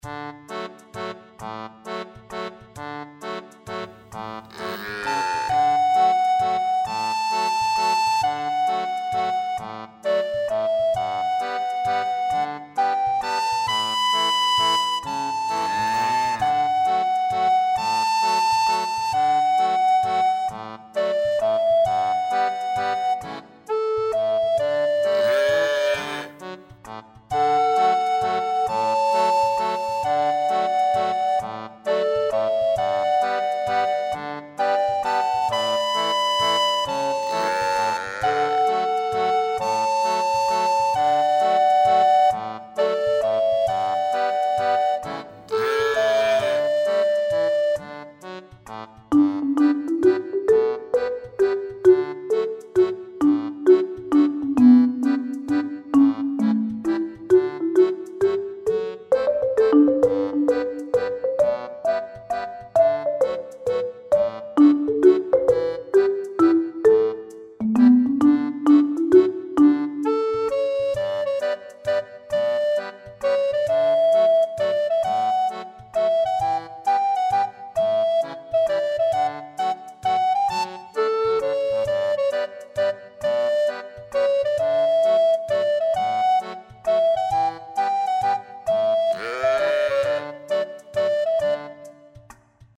ファンタジー系フリーBGM｜ゲーム・動画・TRPGなどに！